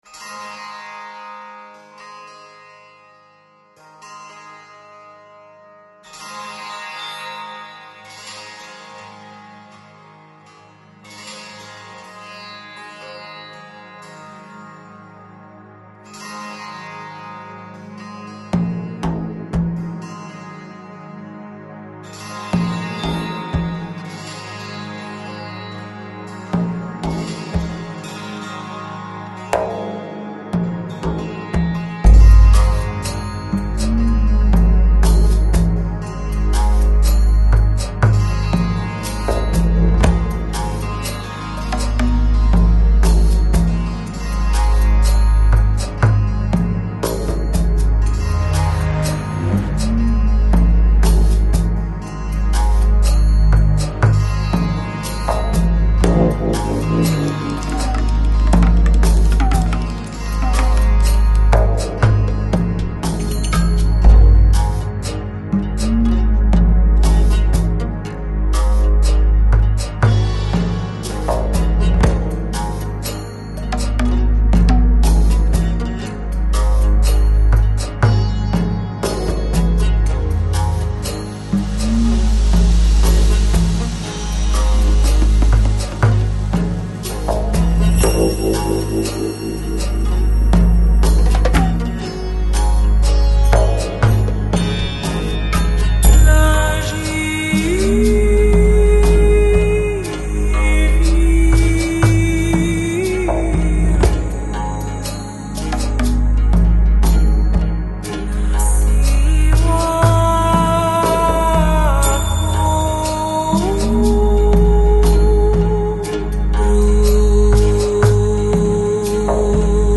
Жанр: Downtempo, Chillout